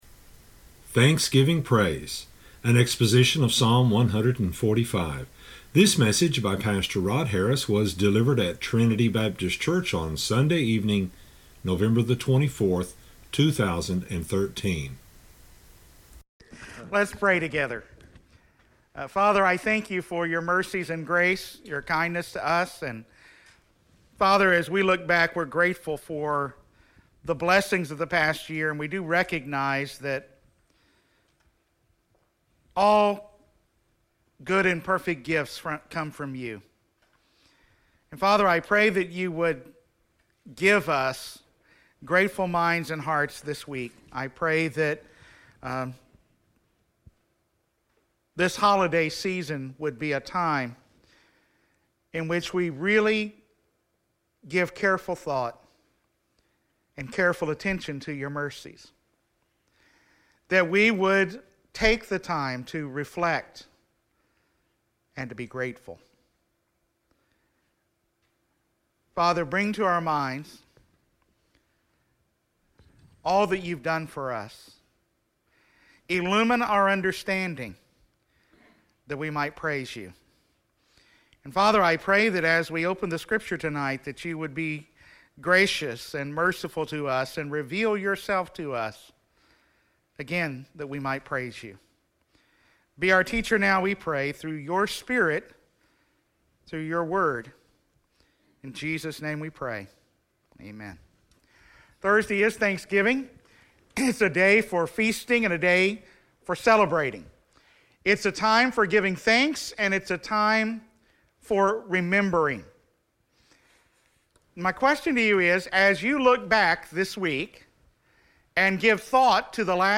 delivered at Trinity Baptist Church on Sunday evening